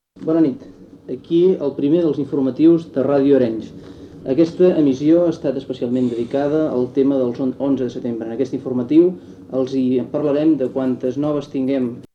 Primer informatiu amb motiu de la Diada Nacional de Catalunya
Informatiu